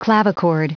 Prononciation du mot clavichord en anglais (fichier audio)